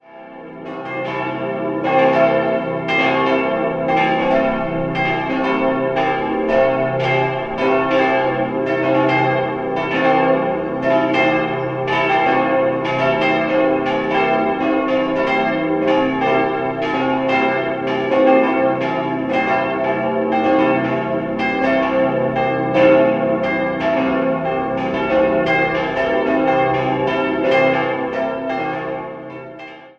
Sie ist ein 1712 begonnener Neubau, der vom Erdinger Stadtmaurermeister Anton Kogler errichtet und 1723 geweiht wurde. Im Inneren besitzt die Kirche eine schöne barocke Ausstattung. 4-stimmiges Salve-Regina-Geläut: c'-e'-g'-a' D ie drei größeren Glocken wurden im Jahr 1947 von Karl Czudnochowsky in Erding gegossen.